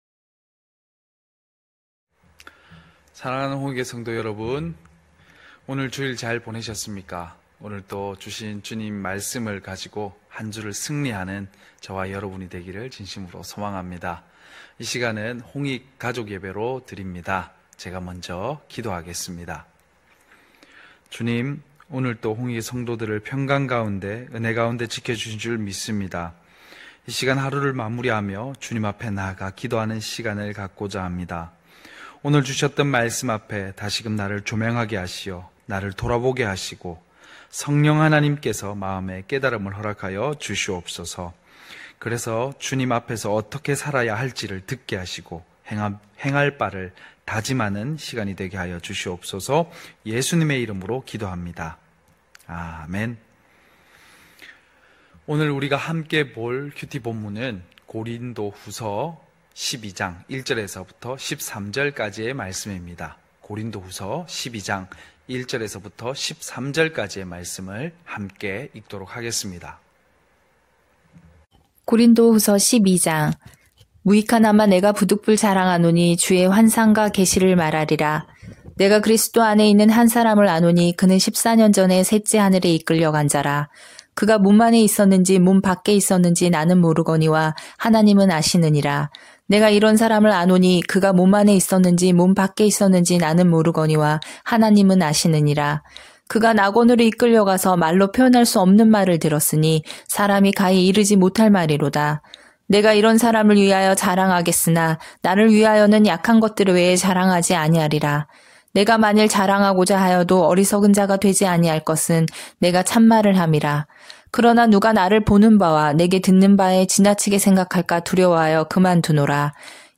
9시홍익가족예배(10월11일).mp3